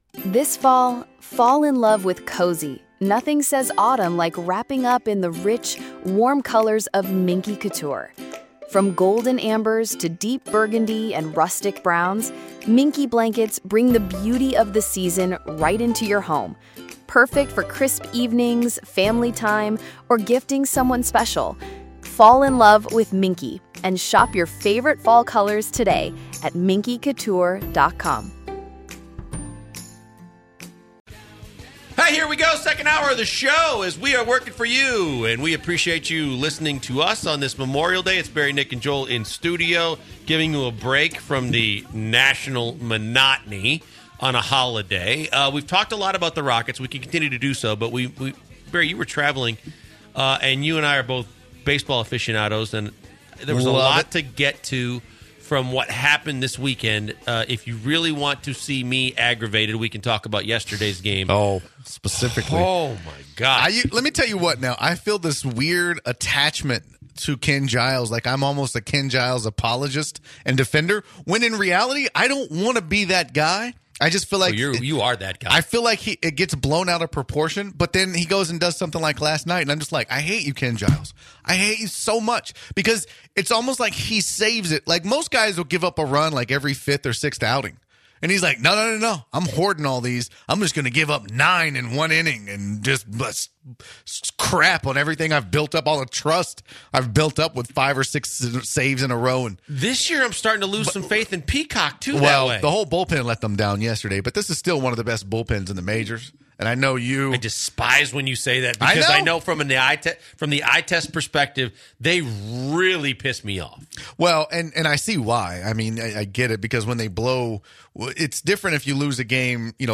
05/28/2018 The Usual Suspects debate the Astros bullpen struggles